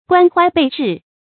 關懷備至 注音： ㄍㄨㄢ ㄏㄨㄞˊ ㄅㄟˋ ㄓㄧˋ 讀音讀法： 意思解釋： 關心得無微不至 出處典故： 劉白羽《海天集 偉大創業者》：「總理對人總是 關懷備至 ，體貼入微。」